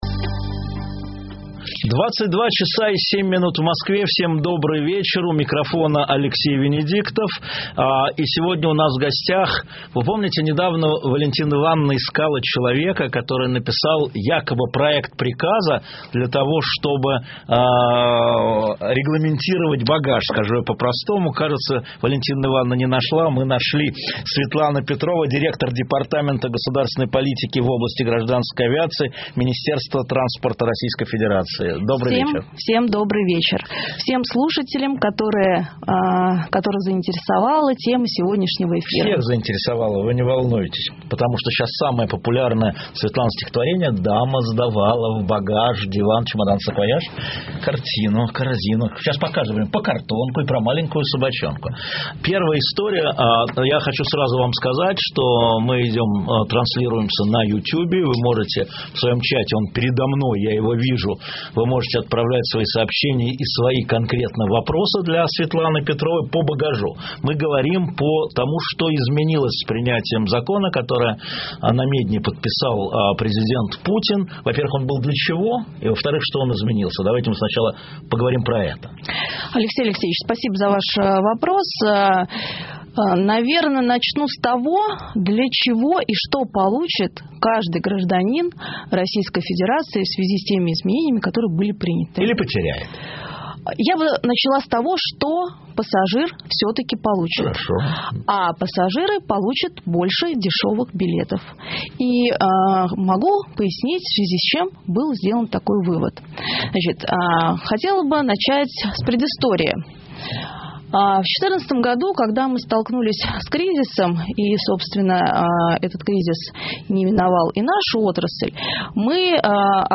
Интервью директора Департамента государственной политики в области гражданской авиации Минтранса России Светланы Петровой в эфире радиостанции «Эхо Москвы»